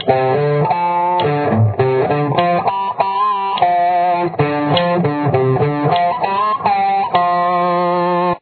Guitar Solo